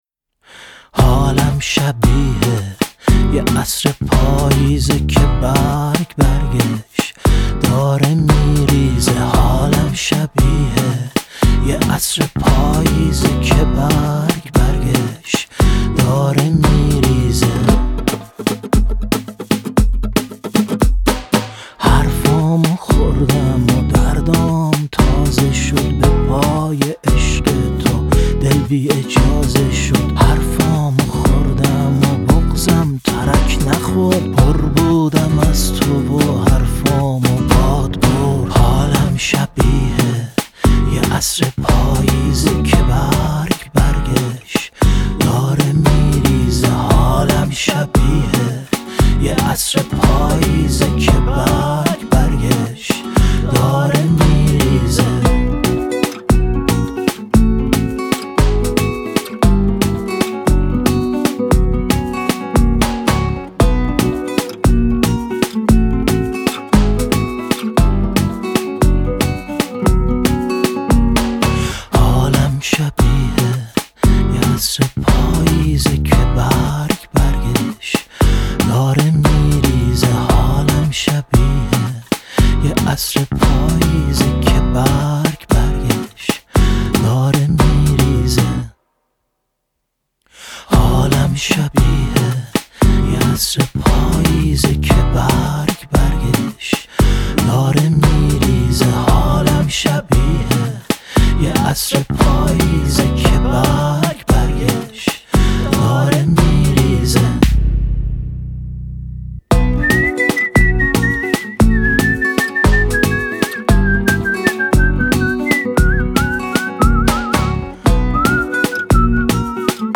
سبک : راک